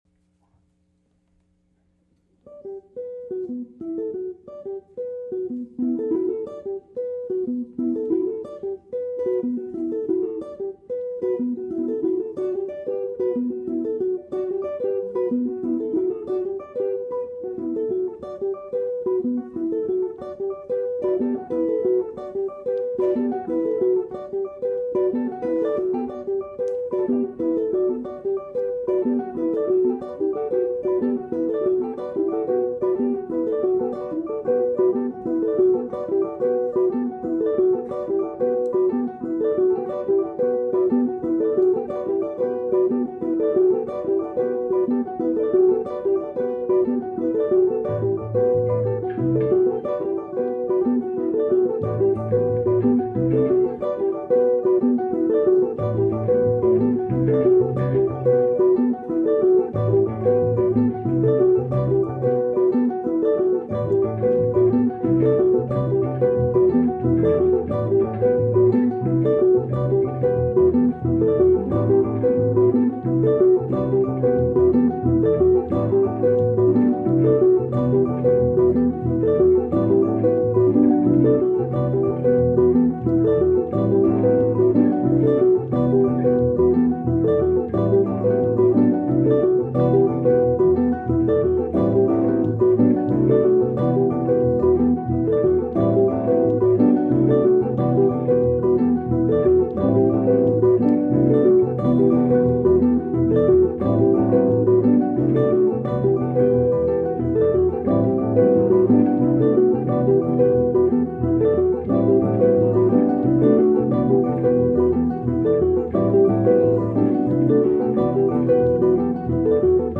A really cool minimalist piece by Steve Reich that was recorded at one point by Pat Metheny with overdubs. We performed it with ELEVEN electric guitars, two electric basses, and a vibes player!